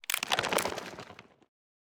creaking_idle1.ogg